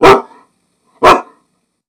We will take the sound of a (dry) dog bark and convolve it with an impulse response to create a bark with reverb: